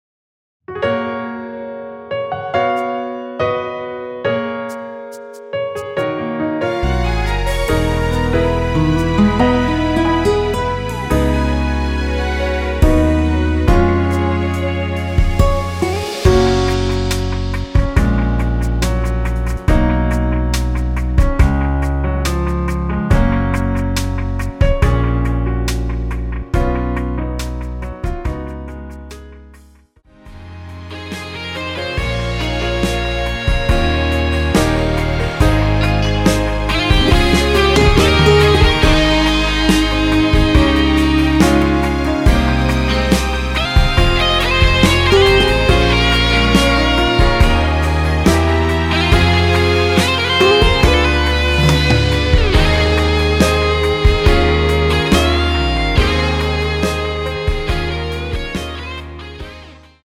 F#
앞부분30초, 뒷부분30초씩 편집해서 올려 드리고 있습니다.
중간에 음이 끈어지고 다시 나오는 이유는